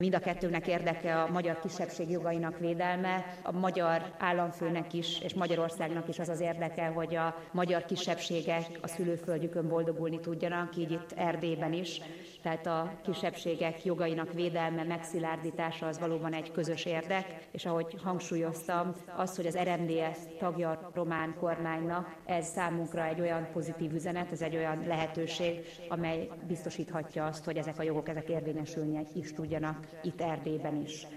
Közös sajtótájékoztatót tartott Bukarestben Klaus Iohannis államfő és Novák Katalin magyar köztársasági elnök. A két államfő beszélt a gazdasági és az ágazati együttműködés fejlesztésének lehetőségeiről is.